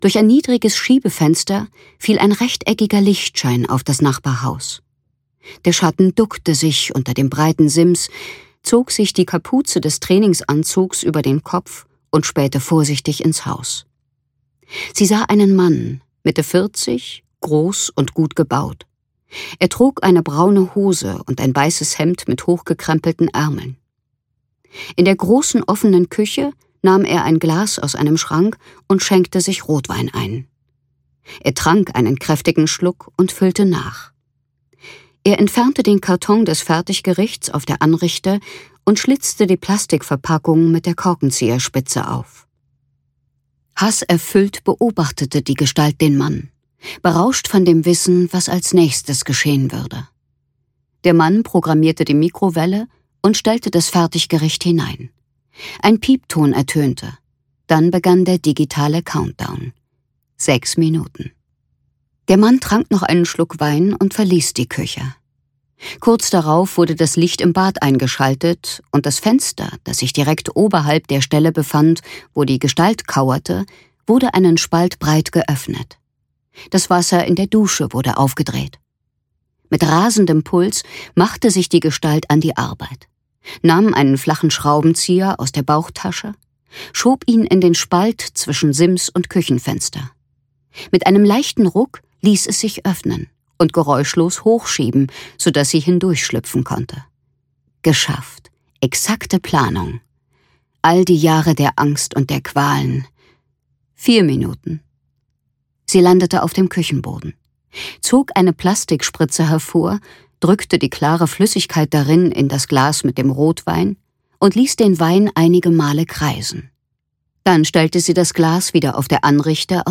Night Stalker (DE) audiokniha
Ukázka z knihy